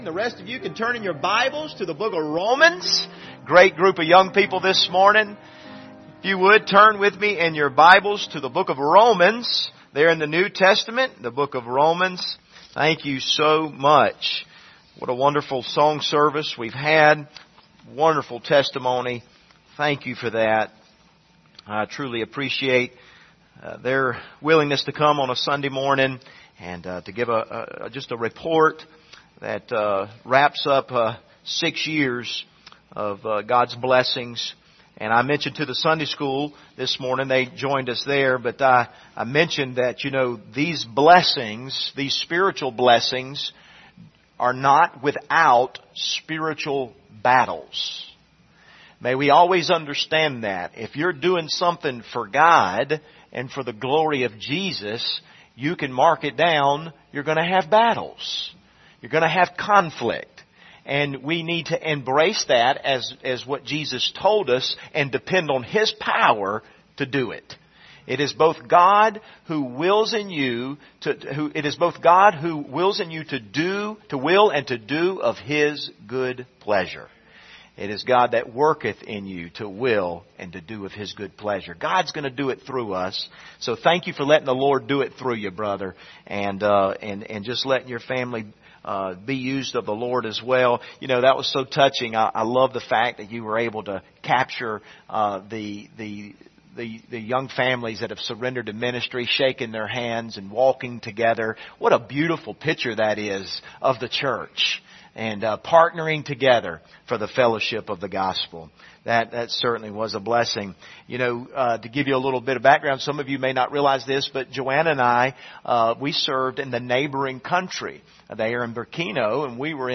Passage: Romans 9:1-3 Service Type: Sunday Morning